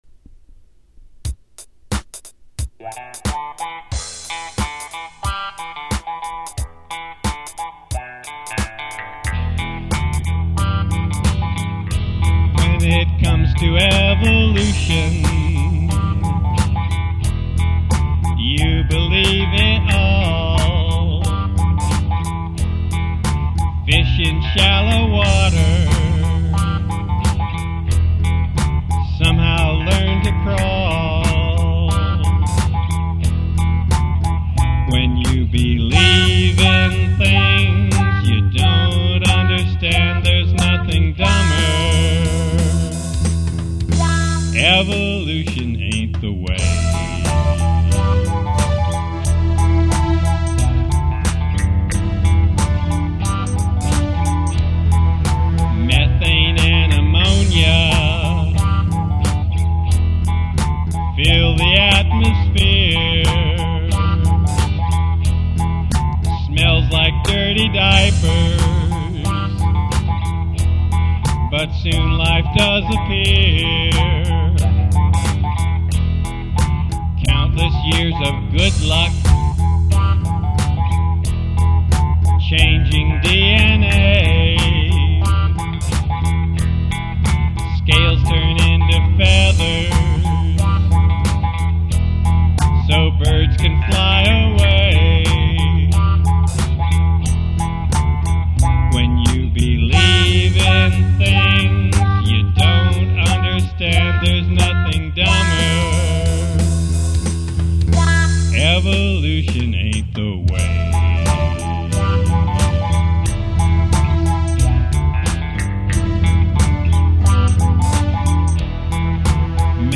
Lead guitar, rhythm guitar, bass guitar, and keyboard